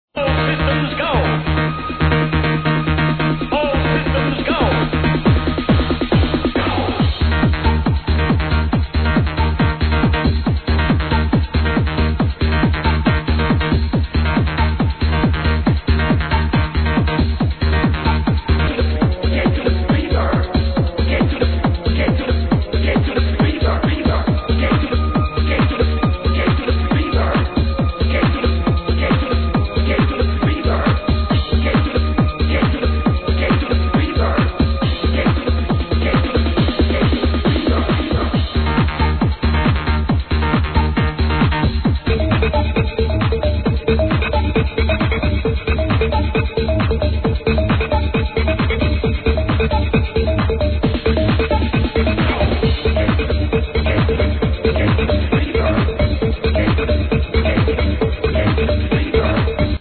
and somewhat similar in the hardstyle of "mrs wood".
low quality sample: